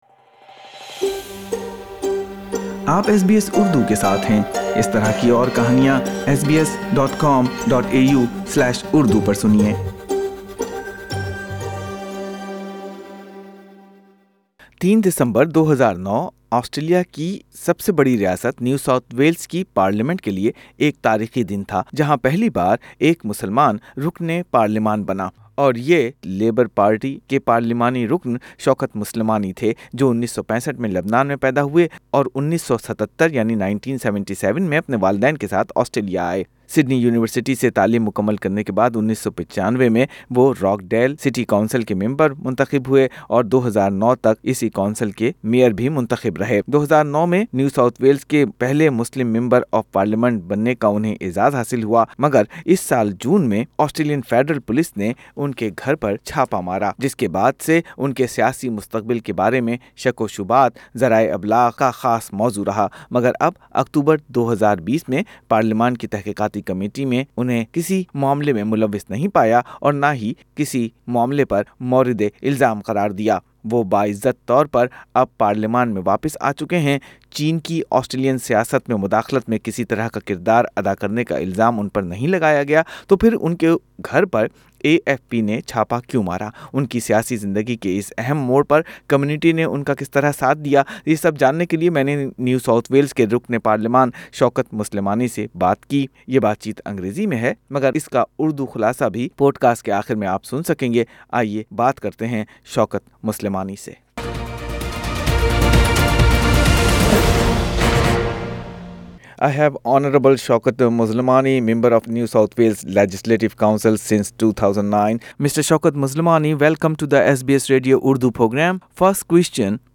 نیو ساؤتھ ویلز کے پہلے مسلم رکنِ پارلیمان شوکت مسلمانی کے دفتر پر چین کے زیر اثر ہونے کے الزامات، فیڈریل پولیس کے چھاپے اور پھر پارلیمان میں با عزت واپسی کی کہانی سنئیے خود شوکت مسلمانی کی زبانی۔